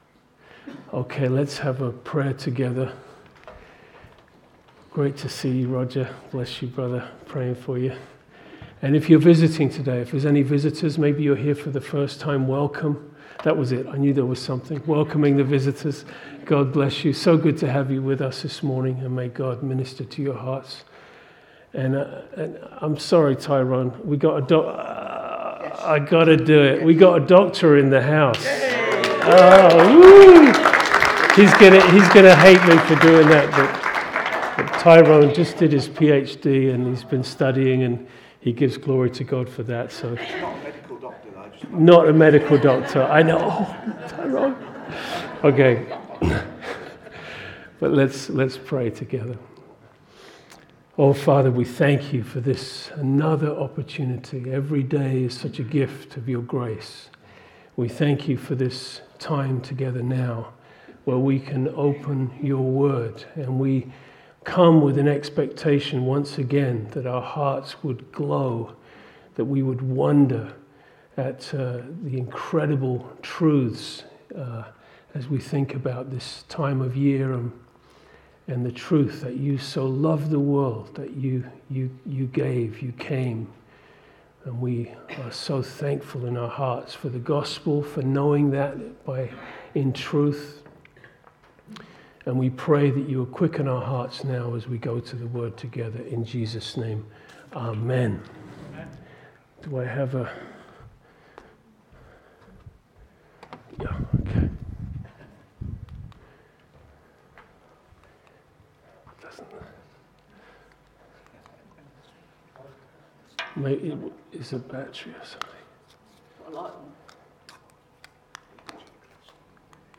at Peacehaven Evangelical Free Church.